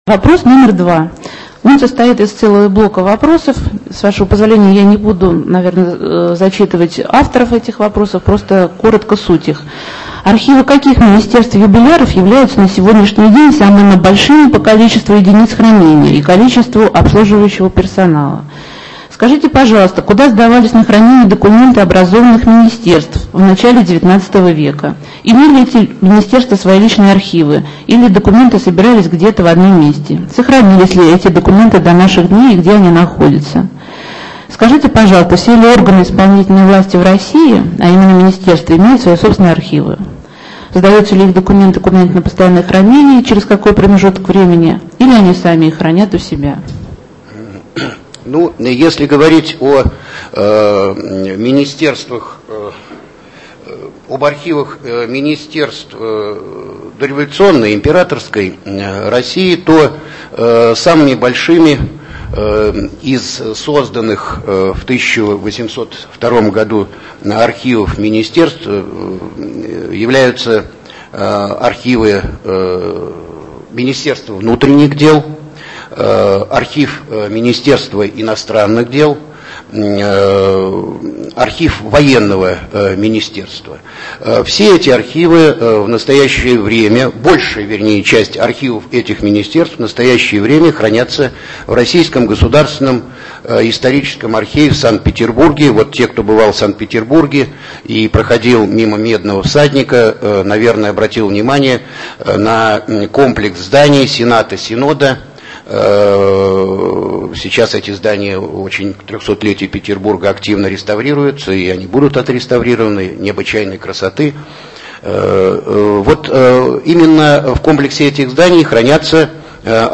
Интернет-конференция